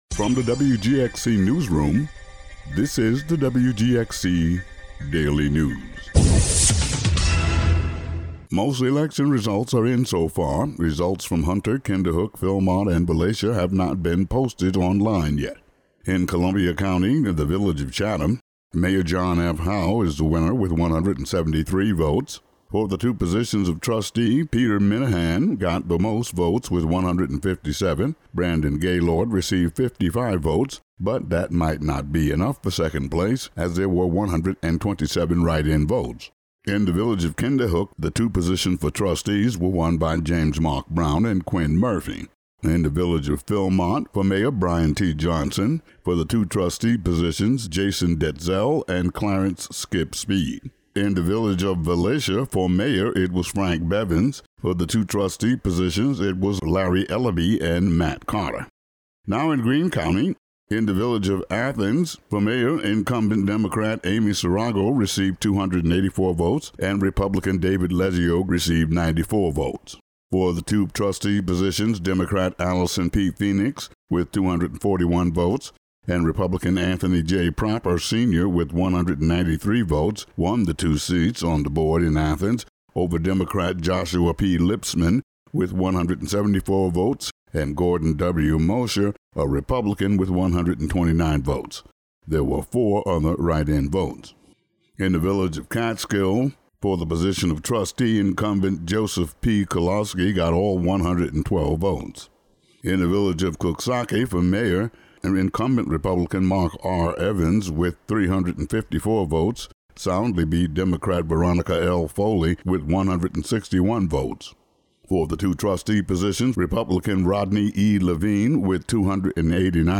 Today's daily news audio update.